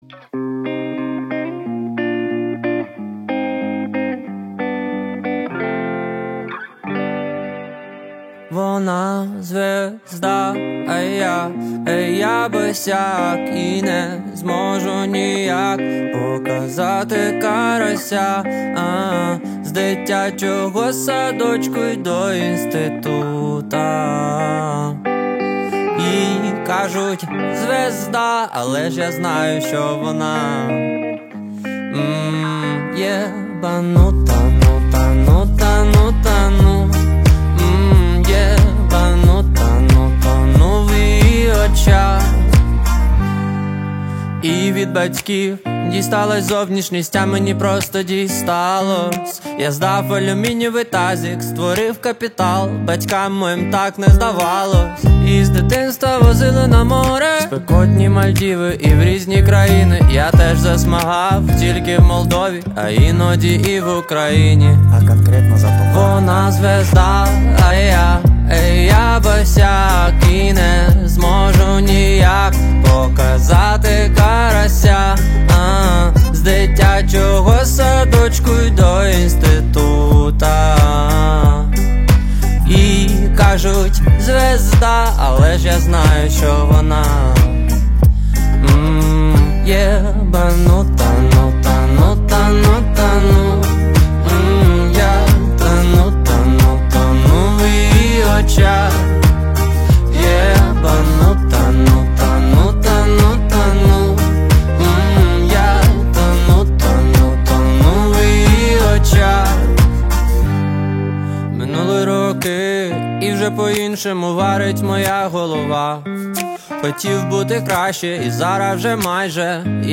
• Жанр:Альтернатива